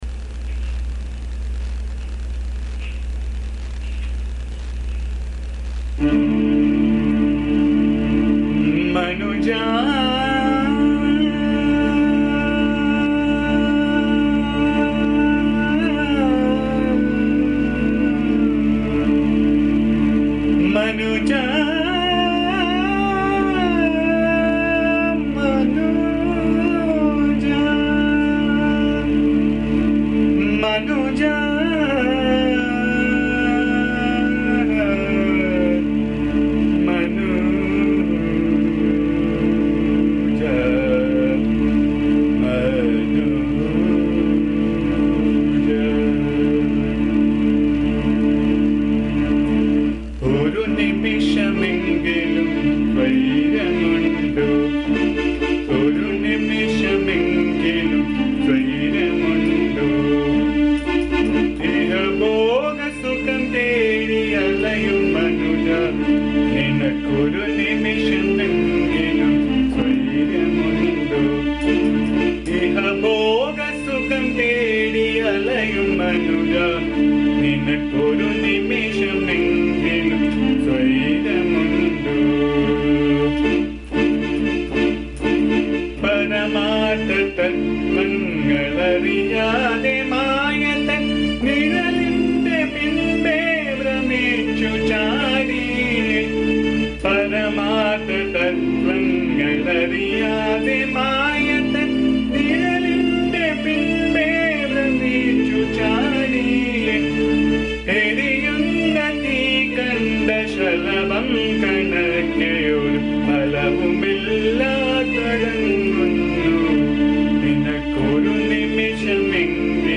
The song is set in Raaga Abheri (also known as Bhimpalas).
Please bear the noise, disturbance and awful singing as am not a singer.